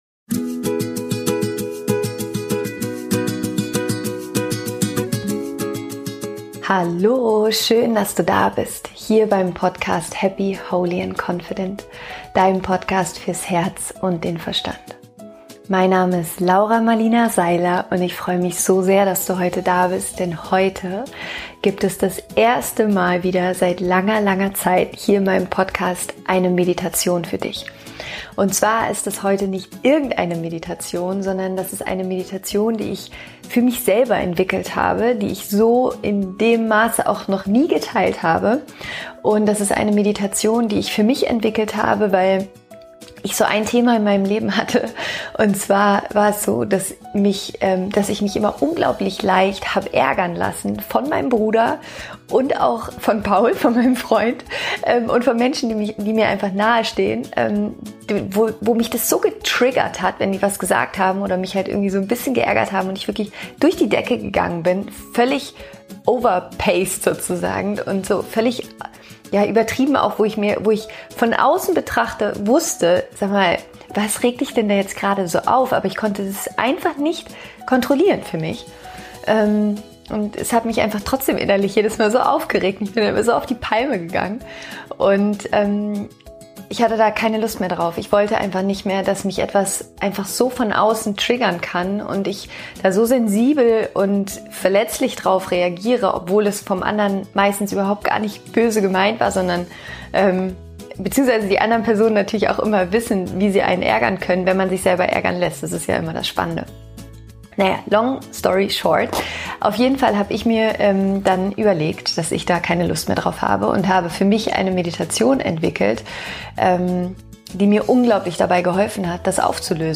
Meditation: Tschüss Trigger, hallo Heilung!